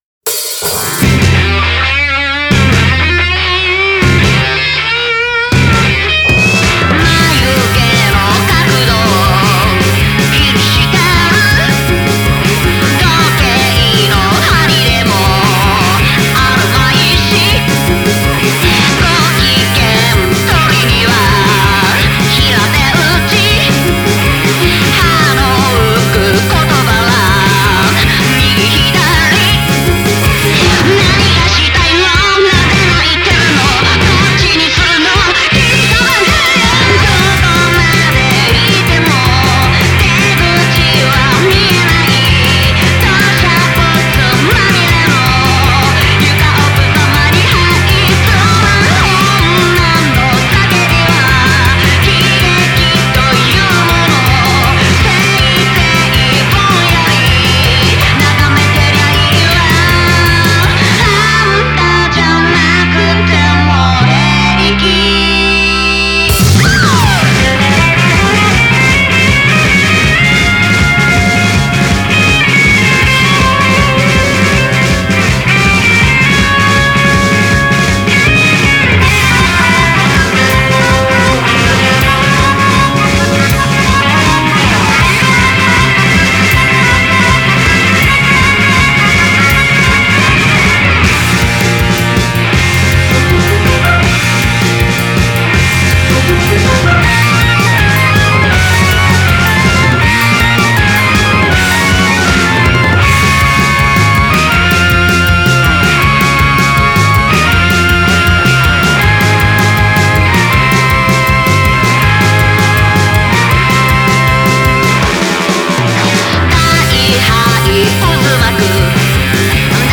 Genre: Psychedelic Rock, Garage